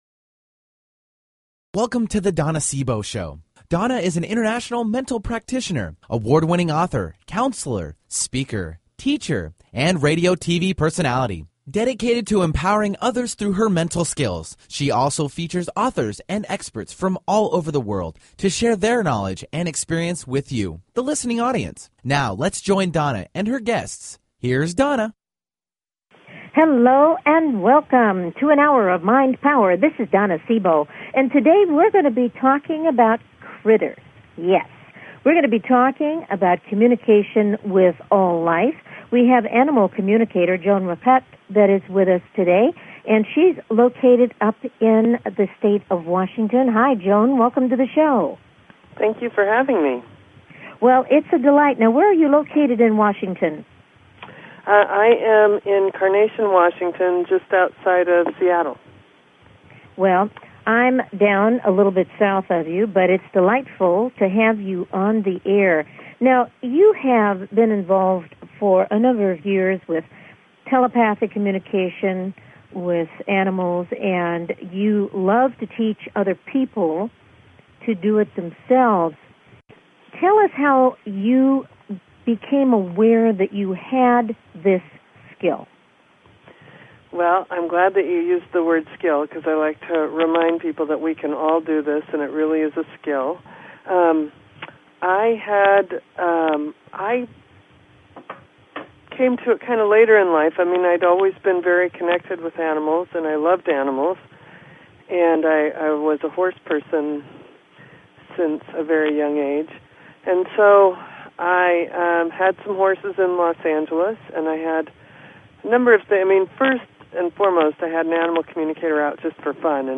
Talk Show Episode, Audio Podcast
Her interviews embody a golden voice that shines with passion, purpose, sincerity and humor.